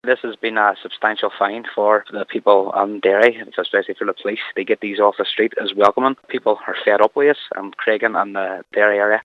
Local Councillor, Colly Kelly has welcomed the discovery………….